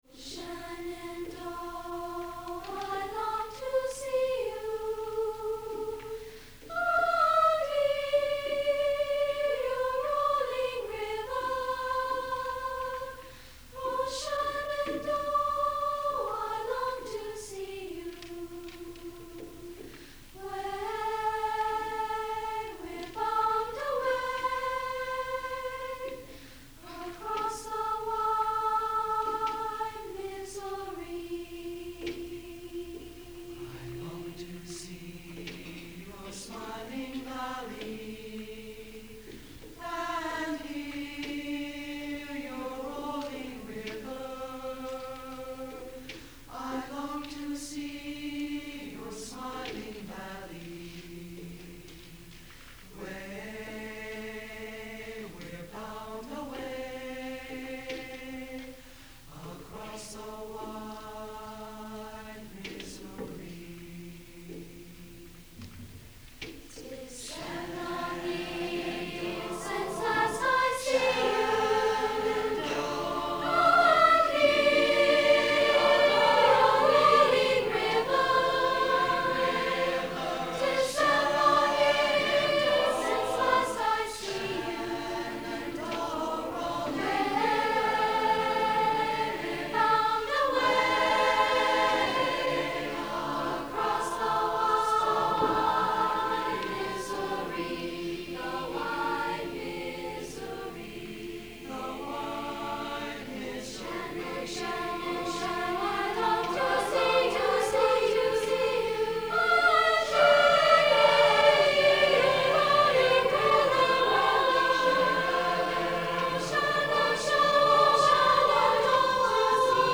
Spring Concert
CHS Auditorium